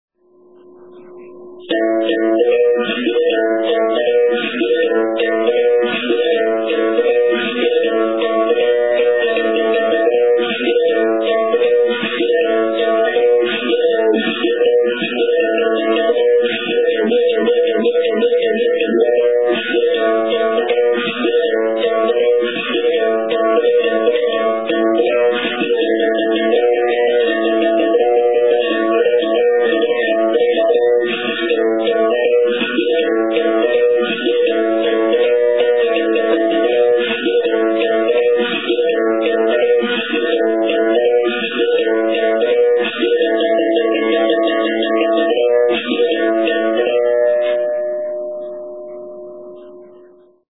Совершая удары бакетой по струне и периодически прижимая монету к последней, беримбау может издавать три основных звука: открытый (тон), закрытый (тин) и жужжащий звук слегка прижатой к струне монеты (чи).
Сао Бенто Гранди ди Бимба